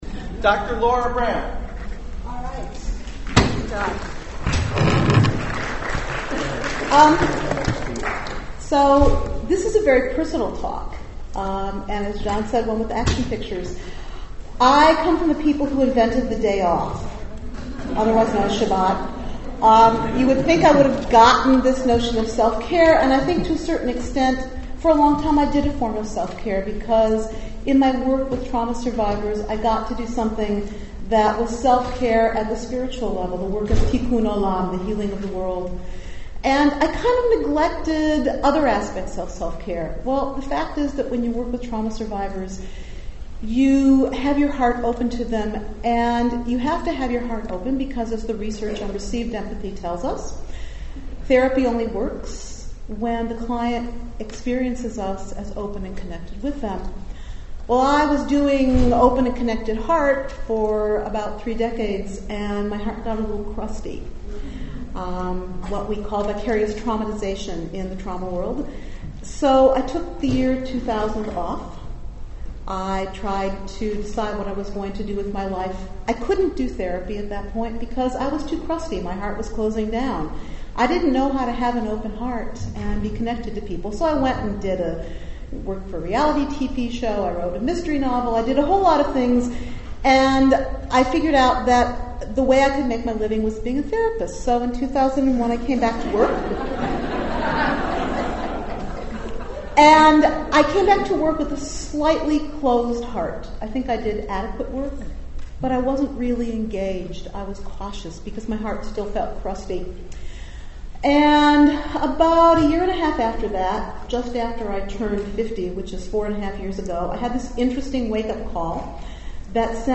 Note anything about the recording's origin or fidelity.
This is a talk that I gave at APA as part of a symposium about therapist self-care. It’s a highly personal description of how I came to the practice of aikido, and how that practice has transformed my capacities to care for myself in the work of trauma therapy.